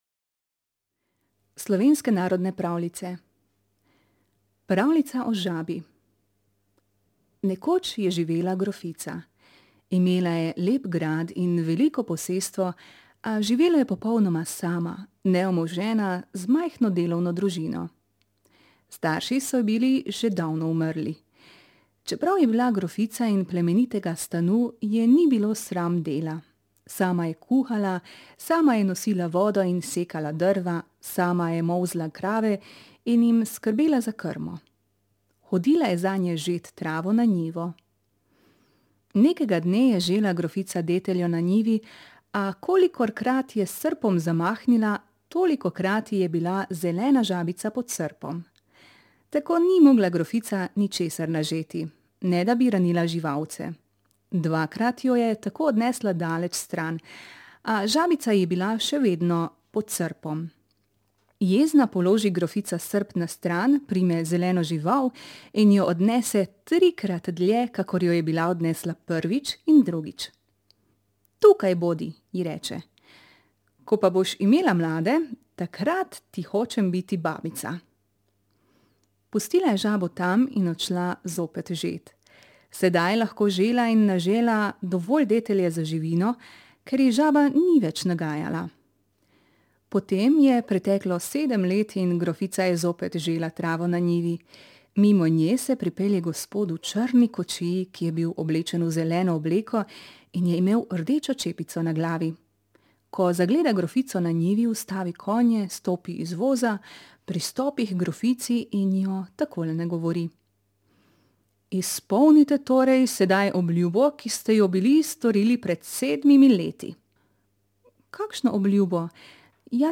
Zvočne pravljice